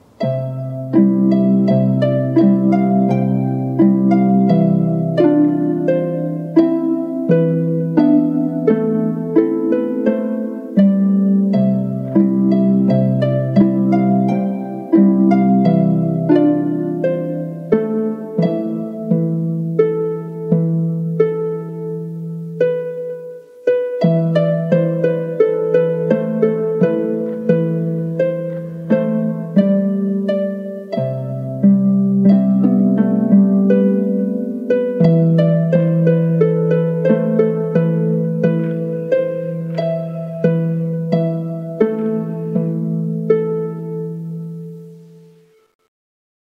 • Качество: высокое
Торжественная мелодия и слова патриотической песни \